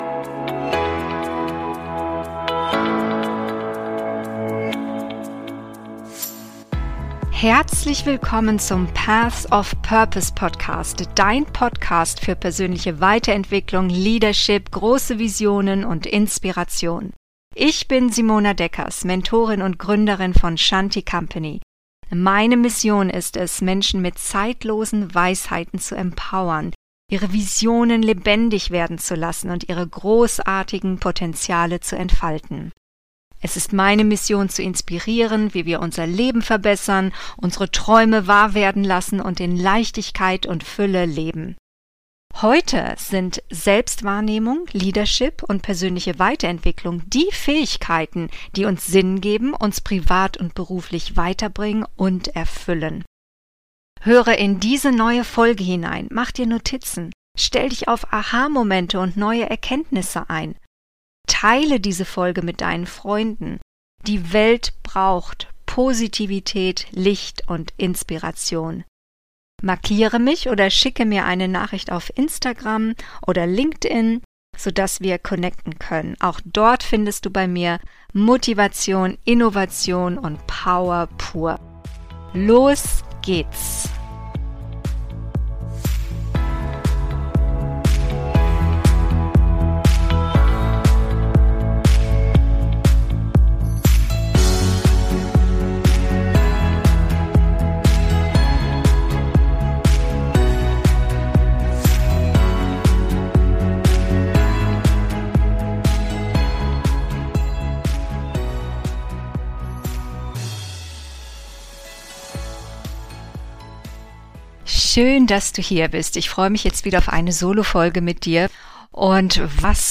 Die Zeit, in der wir leben... Bist Du bereit für diese Zeit? - Solofolge